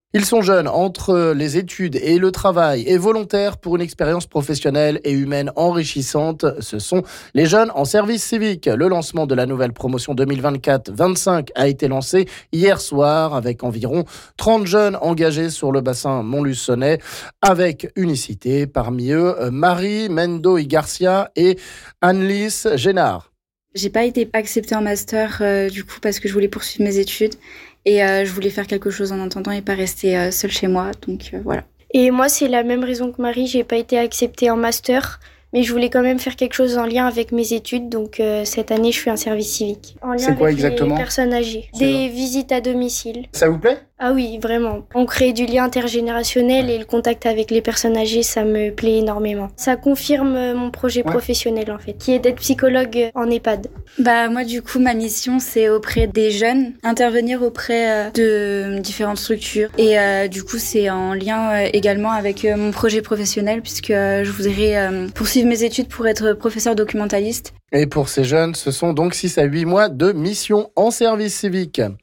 Témoignage ici de 2 jeunes en service civique...